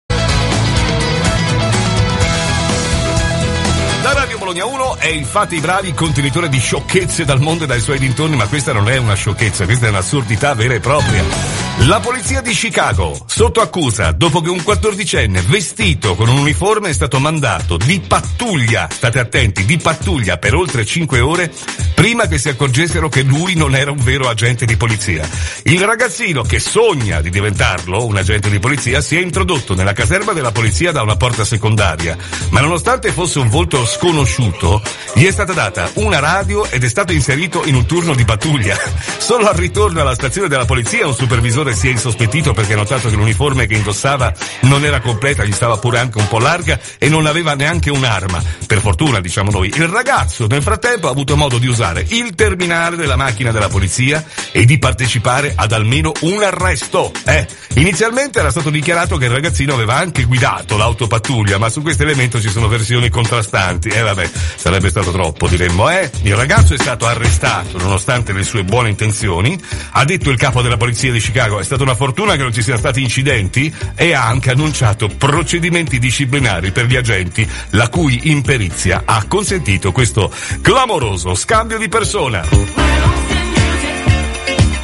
- voice track settato a volume 15
- sottofondo (parte a volume 100 per qualche frame, poi passa a volume 15)
- brano (anche qui l'intro parte con volume a 100 per qualche frame, poi passa a 15)
e i missaggi fra sottofondi e intrro del brano sucessivo non sono piu' fluidi come prima, ma quasi a stacco.
Ti allego un esempio audio, dove puoi sentire il problema: sia quando entra il sottofondo, sia quando entra il brano sucessivo